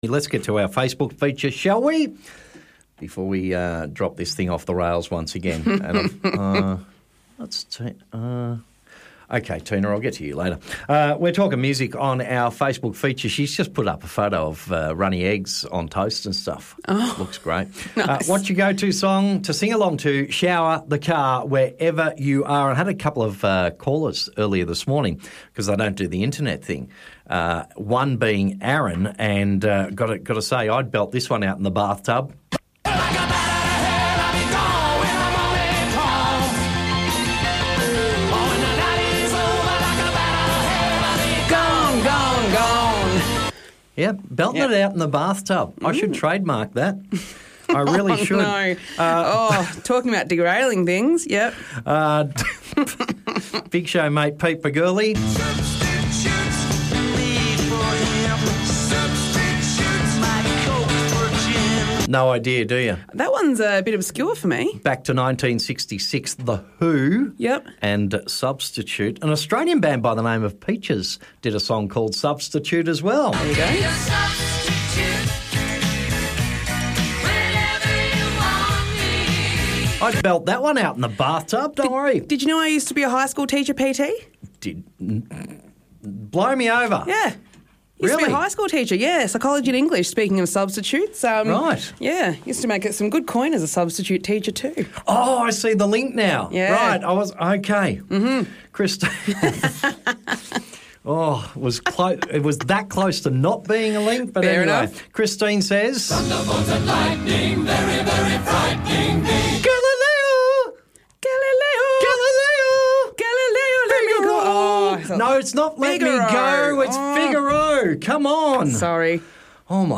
Beware...Bad Singing Alert Part 2